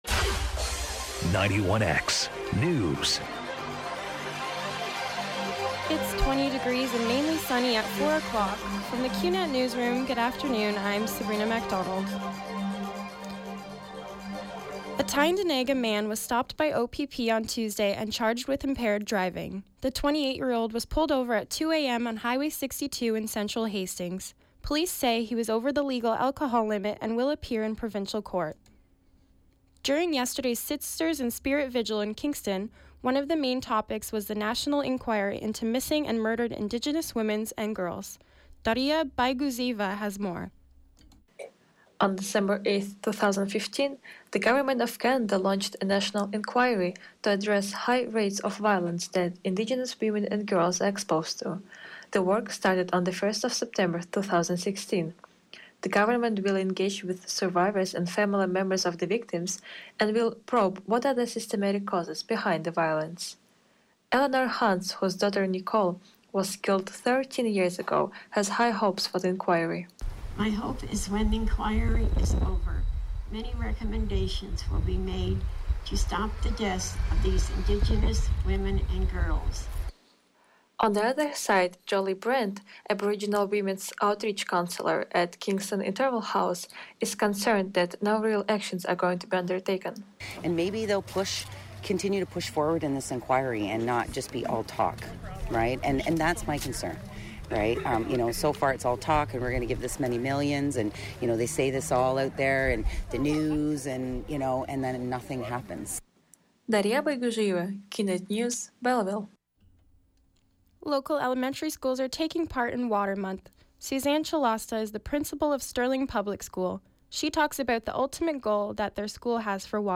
91X Newscast – Wednesday, Oct. 5, 2016, 4 p.m.